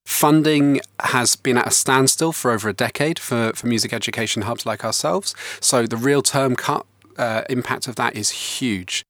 To hear the full interview with Merton Music Foundation, go to the Radio Jackie Facebook page.